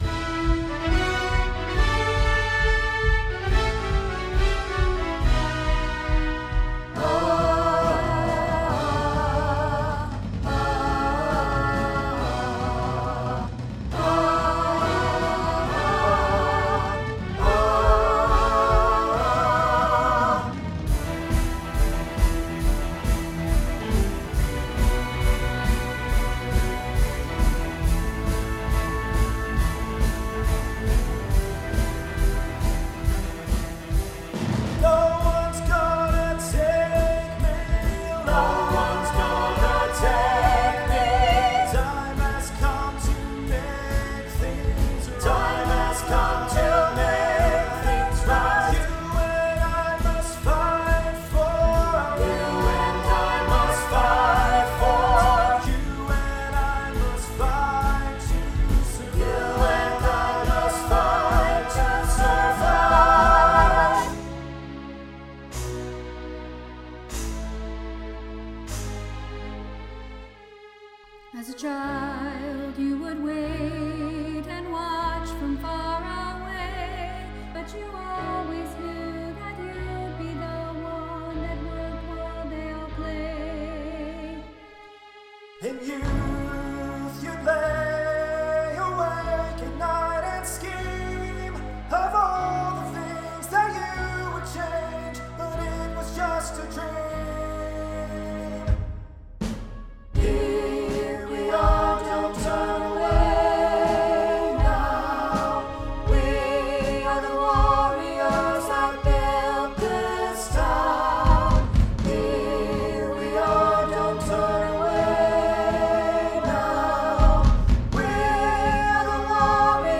SATB
Awesome medley